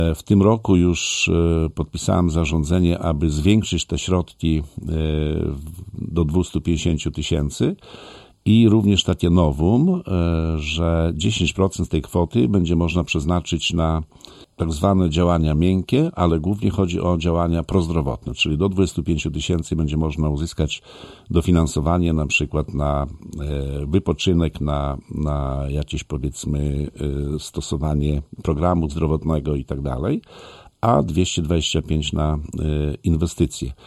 Szczegóły przypomina Wacław Olszewski, burmistrz Olecka.